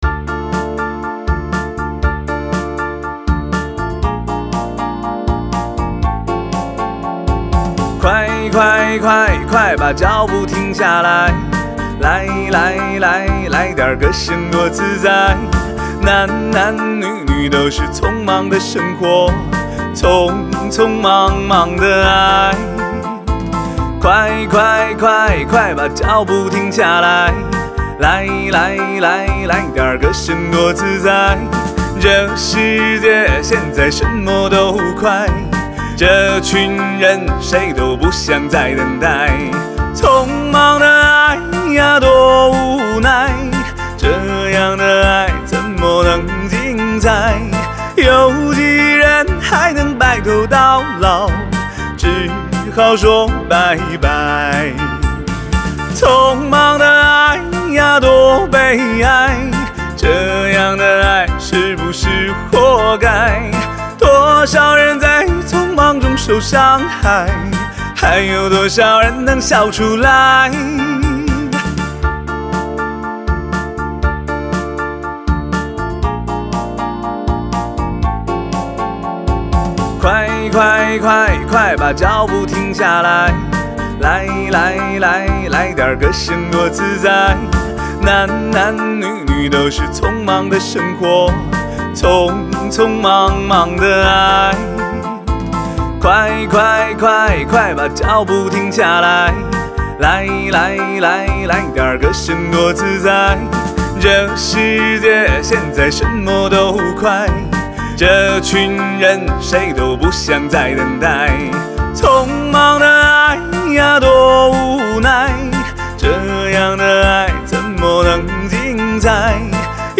曲风：流行